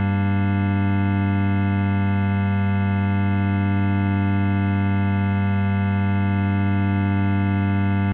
gm-chord.ogg